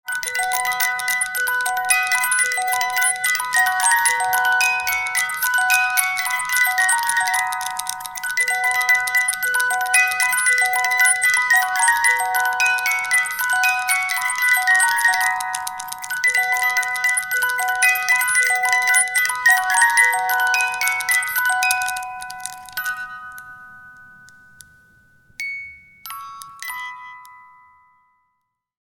Fast And Chaotic Music Box Spinning Sound Effect
Description: Fast and chaotic music box spinning sound effect. Fast spinning small music box playing melody. Distorted and chaotic sound effect. Whirling chaos tune.
Fast-and-chaotic-music-box-spinning-sound-effect.mp3